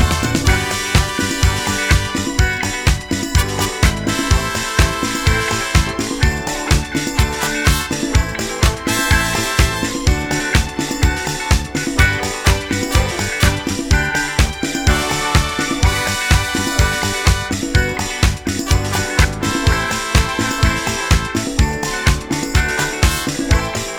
no Backing Vocals Disco 3:51 Buy £1.50